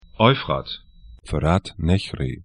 Euphrat 'ɔyfrat Fırat nehri fə'rat 'nɛçri tr Fluss / stream 36°49'N, 38°02'E